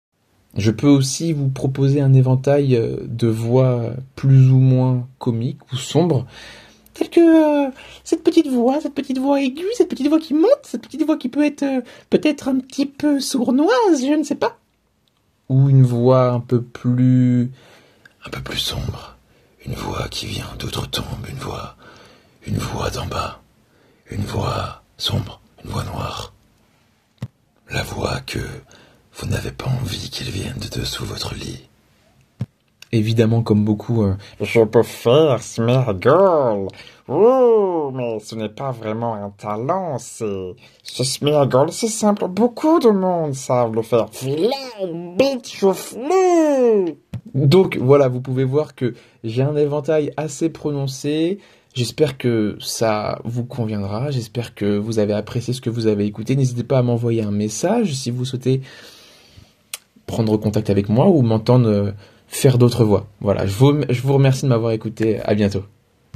Multiples voix